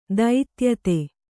♪ daityate